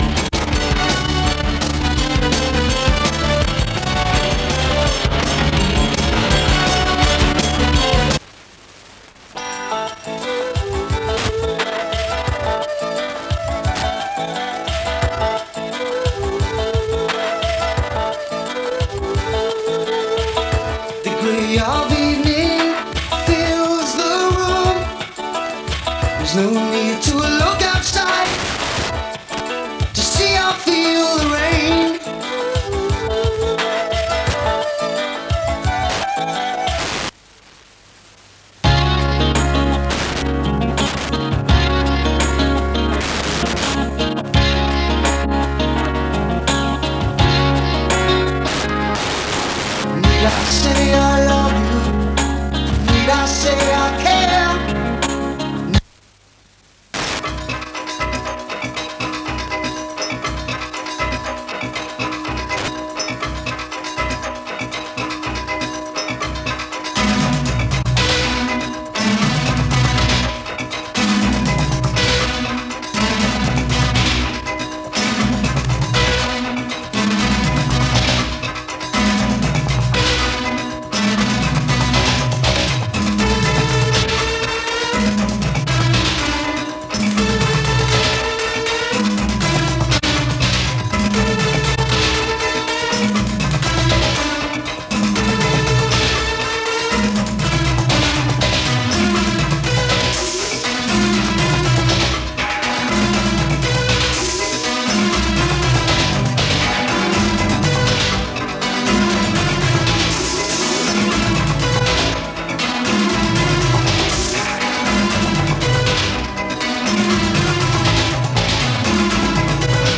New Apr 2025 A short test transmission around mid-day, thought to be on Sunday 15th December 1996. This was the day of the raid on the station. When the transmitter cuts off at the end, a weak identification from South Flinshire Radio can just be heard in the background. Recorded near Blackpool from 105.8MHz, and is a bit noisy. 6MB